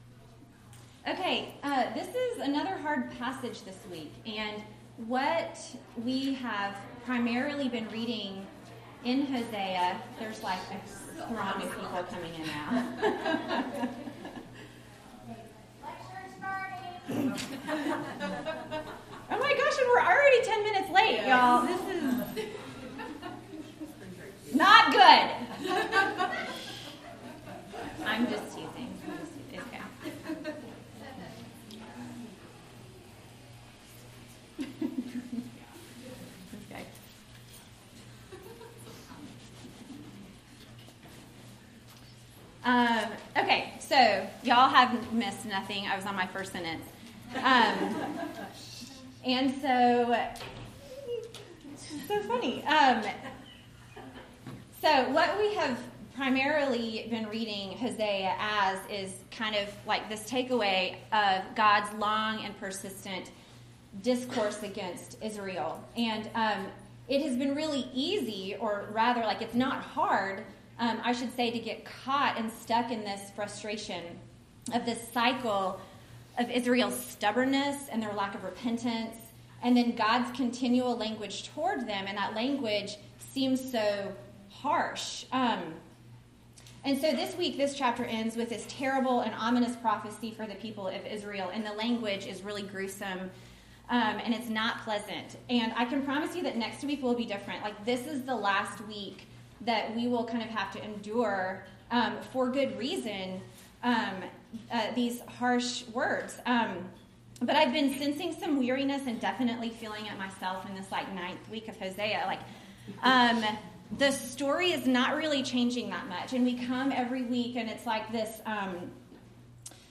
Lecture 2